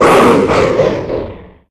infinitefusion-e18/Audio/SE/Cries/DRAMPA.ogg at a50151c4af7b086115dea36392b4bdbb65a07231